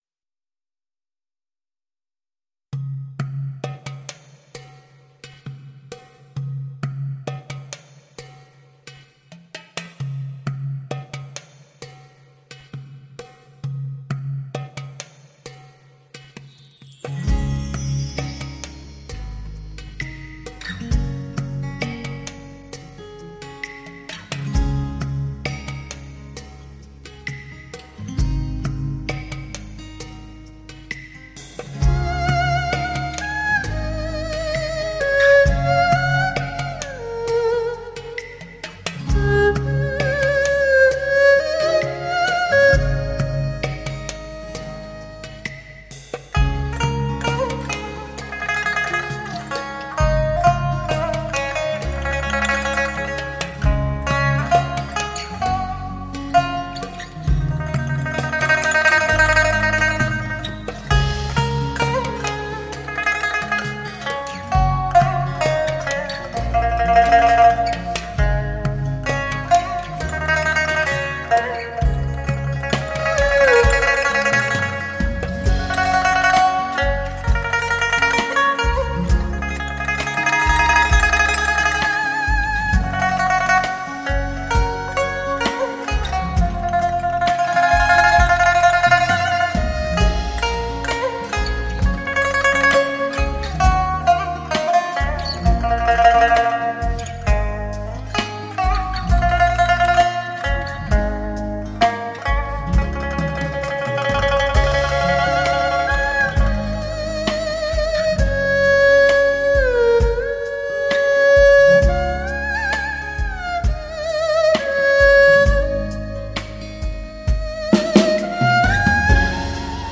令人惊叹的发烧感觉，民族器乐之大成，华夏韵律之最美；经典再现，